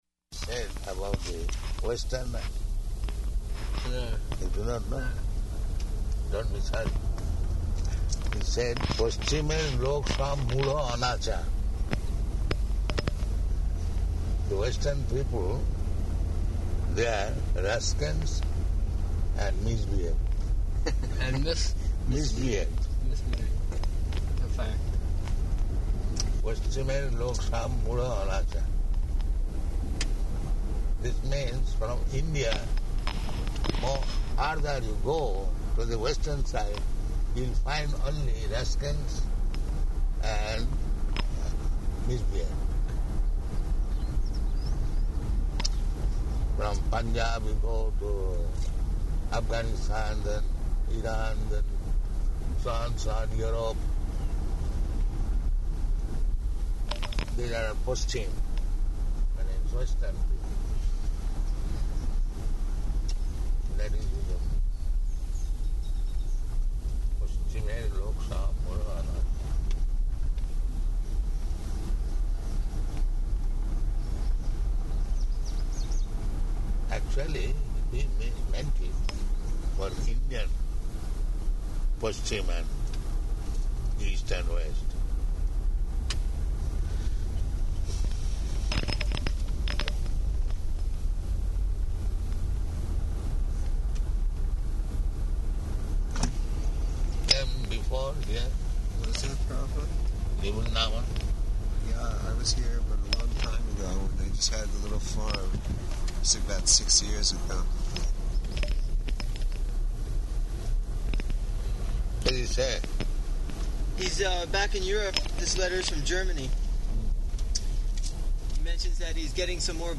Morning Talk in Car [partially recorded]
Type: Walk